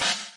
镲片/碰撞 " 碰撞中国3号短镲片
描述：它听起来并不自然，它听起来很棒，作为鼓声填充的一部分，或添加回声。它是用wavelab和手工制作的伊斯坦布尔瓷钹创建的。